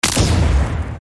shoot_sound_1.mp3